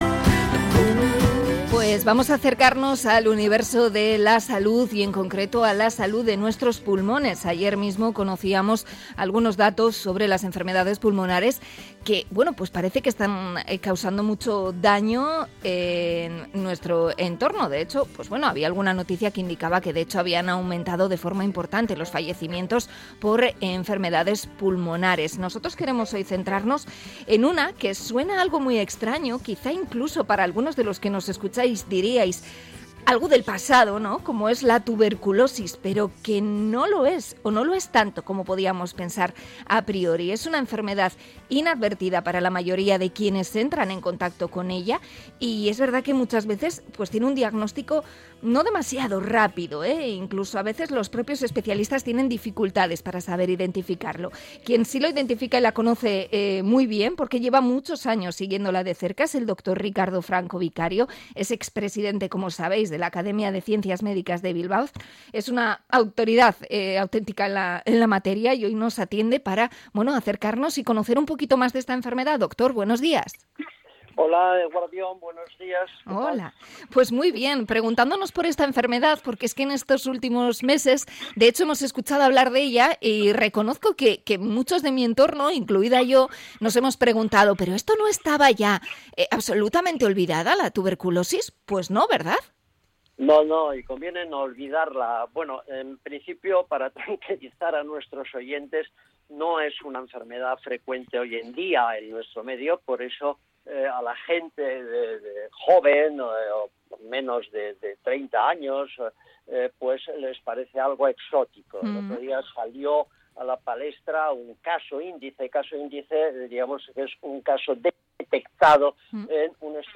Entrevista sobre la tuberculosis